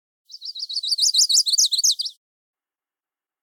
Song of the Chestnut-sided Warbler
242-e-chestnut-sided-type-b12.mp3